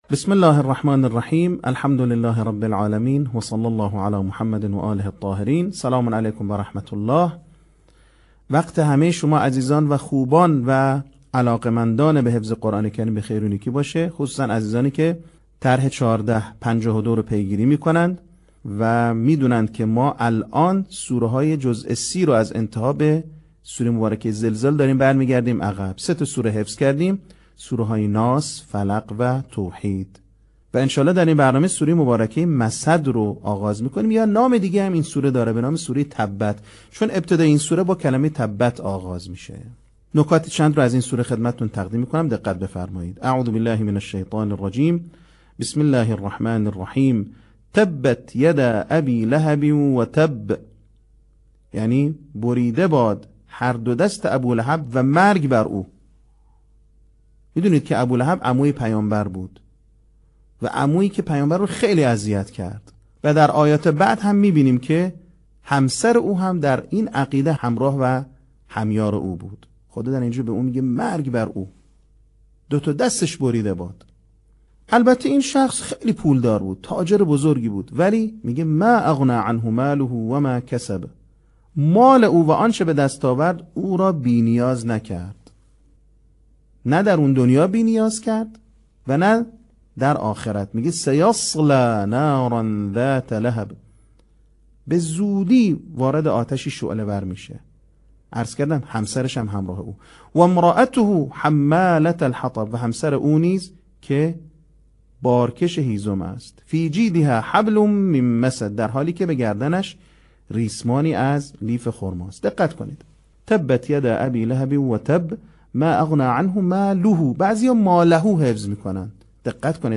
صوت | آموزش حفظ سوره مسد